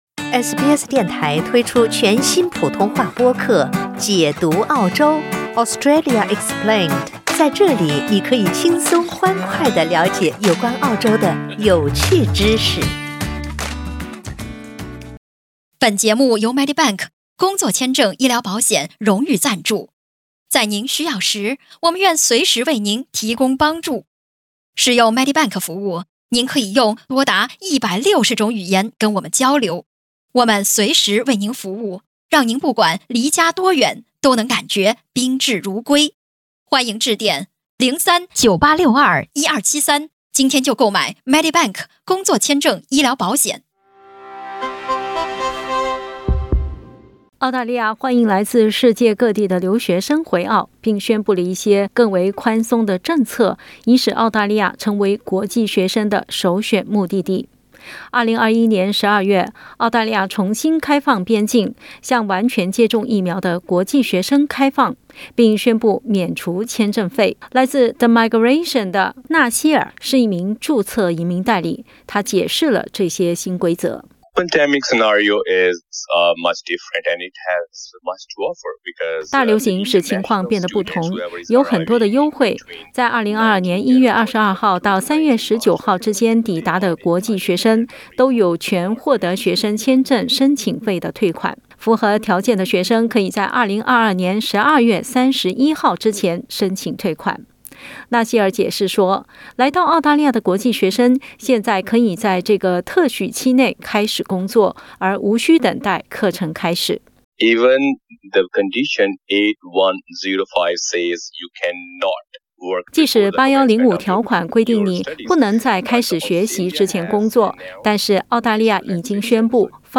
经过近两年的边境限制，澳大利亚重新向国际学生敞开大门。 在放宽边境限制后，政府最近宣布对学生签证持有人放宽限制，以方便国际学生返回澳大利亚。 （点击图片收听报道）